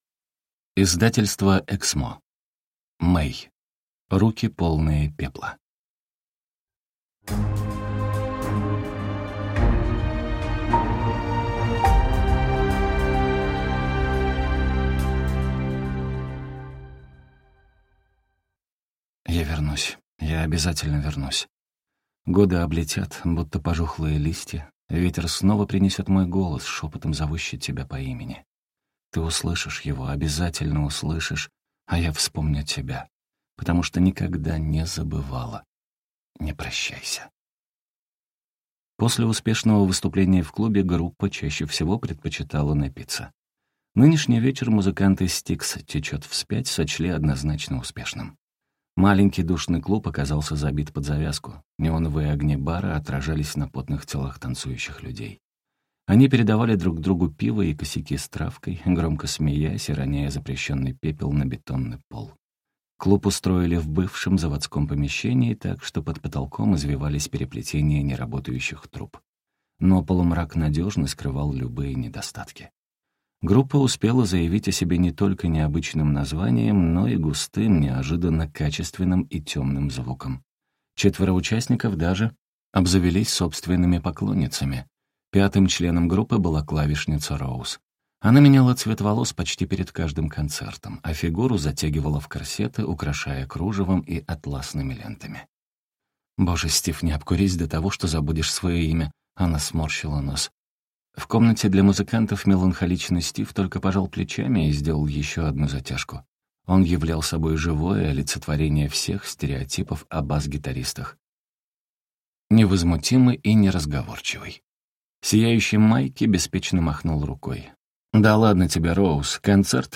Аудиокнига Руки, полные пепла | Библиотека аудиокниг